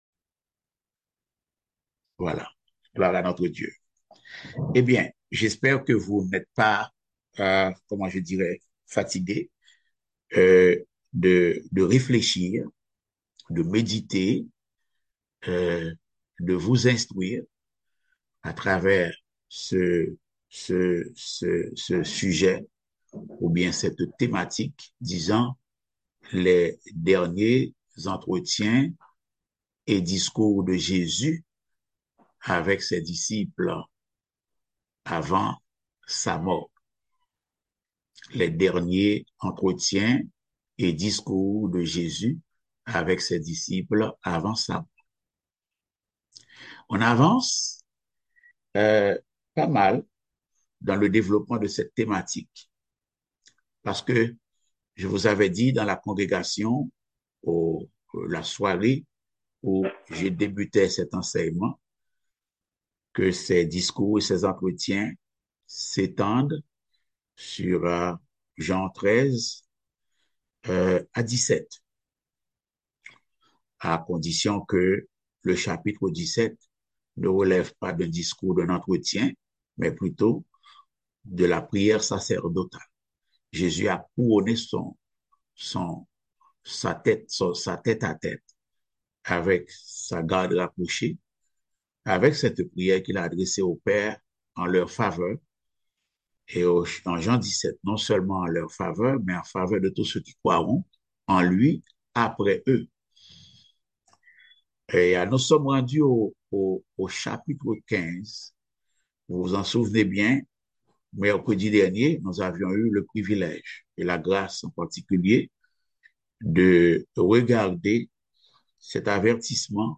Type De Service: Études Bibliques